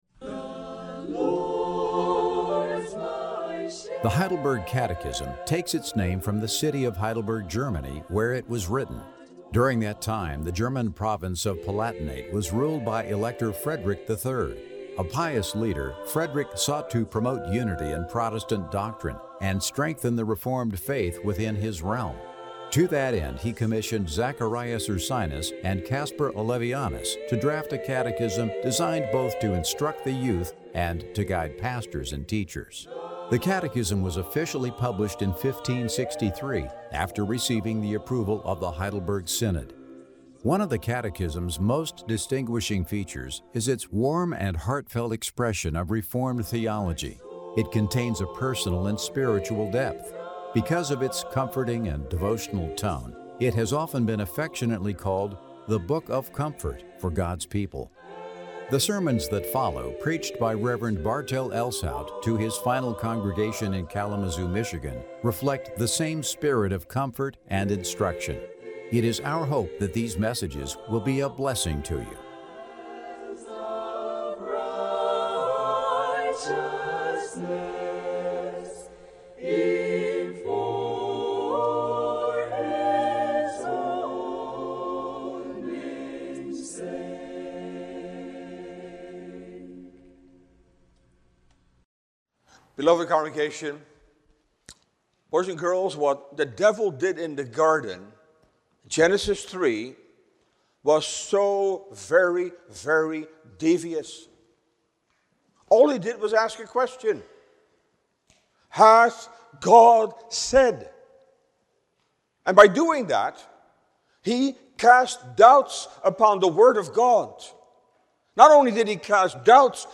Sermon Downloads